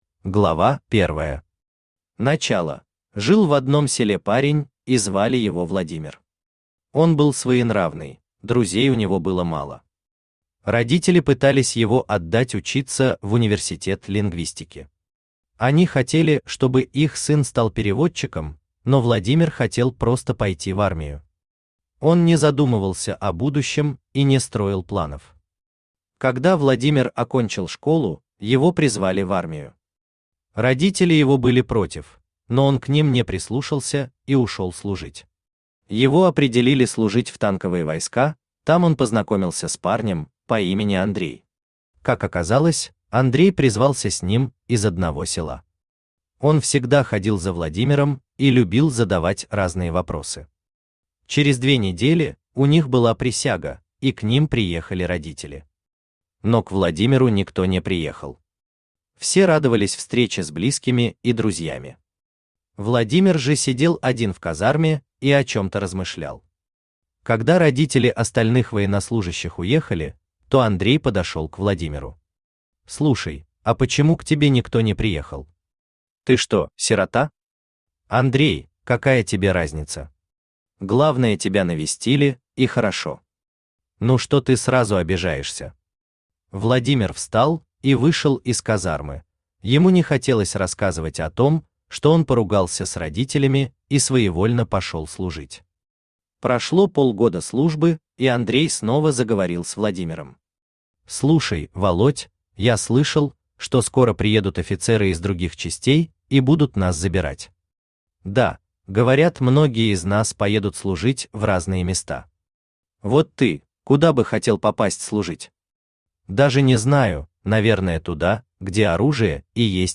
Аудиокнига Секретная служба | Библиотека аудиокниг
Aудиокнига Секретная служба Автор ALEX 560 Читает аудиокнигу Авточтец ЛитРес.